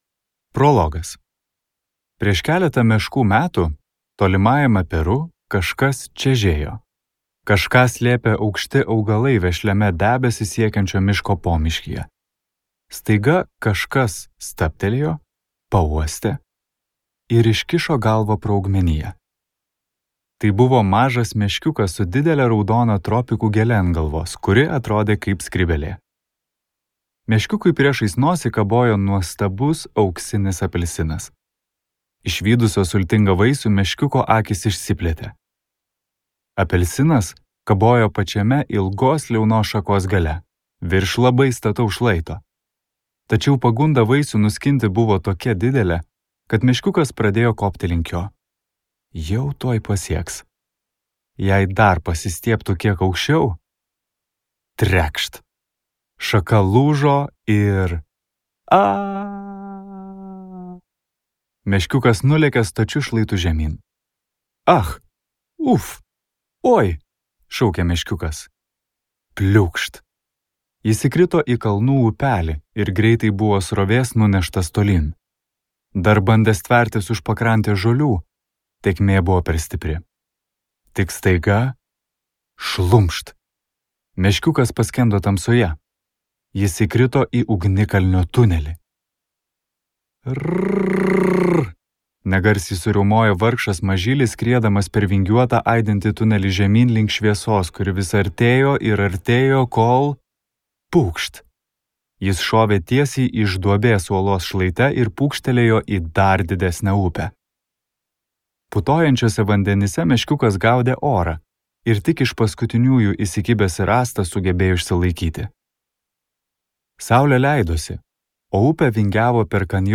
Nuotykiai Peru | Audioknygos | baltos lankos